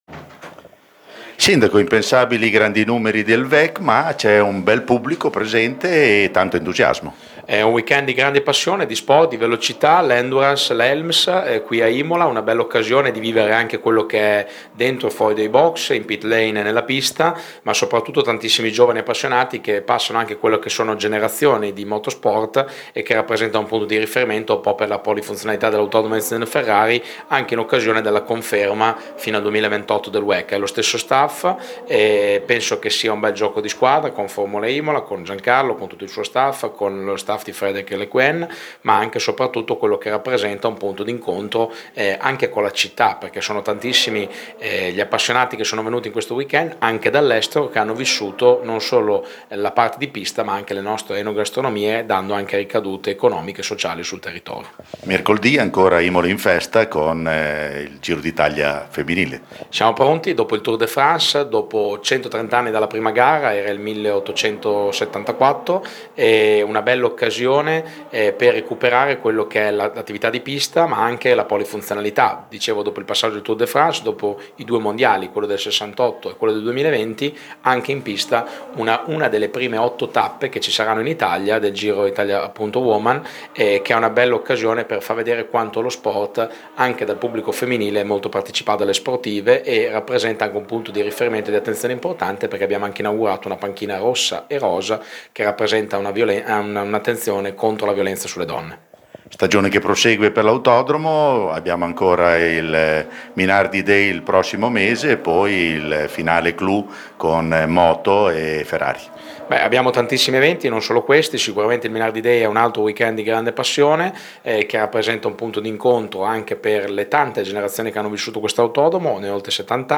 Marco Panieri, sindaco di Imola